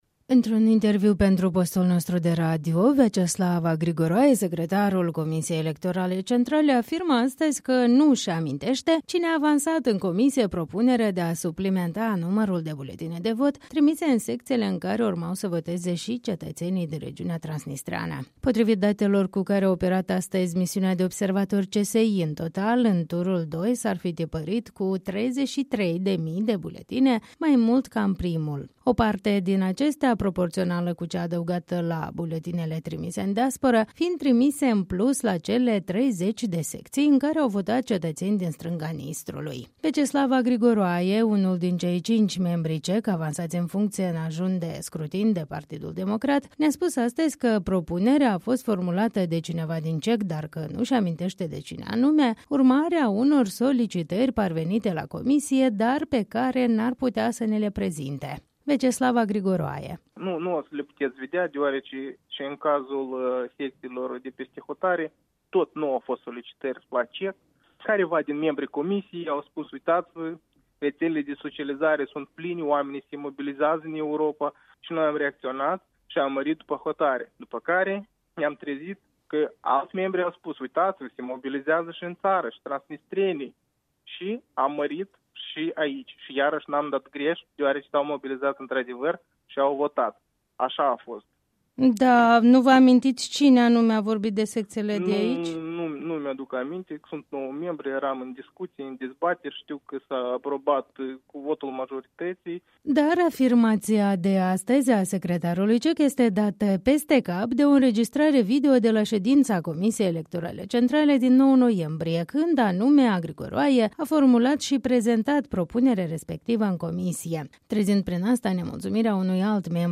Într-un interviu pentru radio Europa Liberă, secretarul Comisiei Electorale Centrale Veaceslav Agrigoroaie afirmă că nu-şi aminteşte cine a avansat în comisie propunerea de a suplimenta numărul de buletine de vot trimise în secţiile în care urmau să voteze şi cetăţenii din regiunea transnistreană.